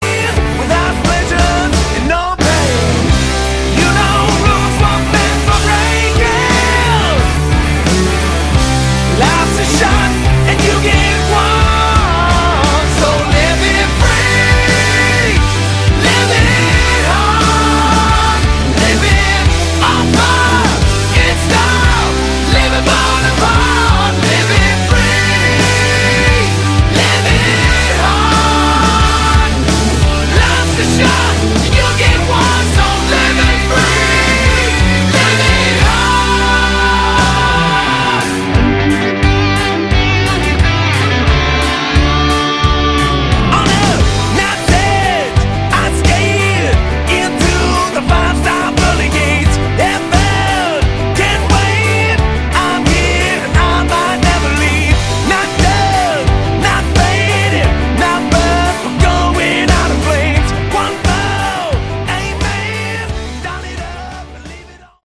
Category: AOR
lead and backing vocals, keyboards
guitars, bass, kayboards
drums
backing vocals
Vocals on track 2 are really rough.